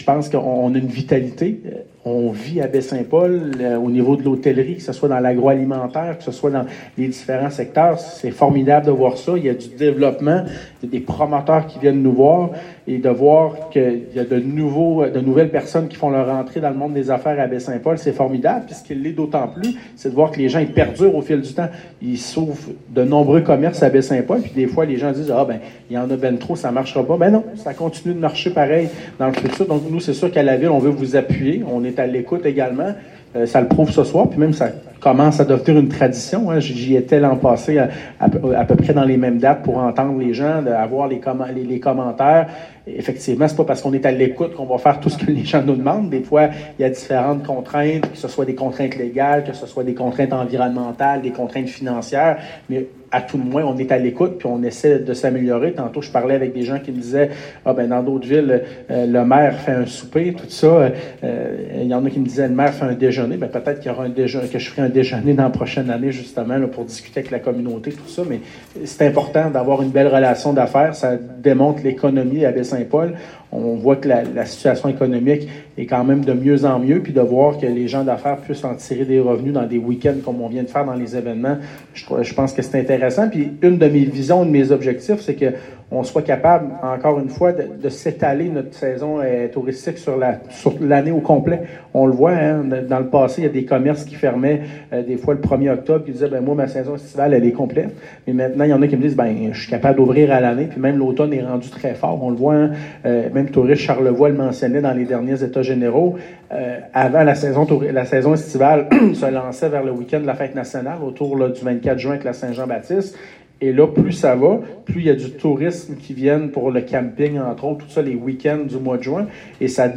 Une trentaine de convives s’étaient rassemblés dans l’ambiance chaleureuse et pittoresque de l’auberge de la rue Saint-Jean-Baptiste pour une séance de questions/réponses sans filtre.
Le maire Pilote avait promis d’aborder les sujets, positifs comme négatifs, en toute transparence, et il a tenu parole, répondant à brûle-pourpoint aux interrogations de la communauté d’affaires.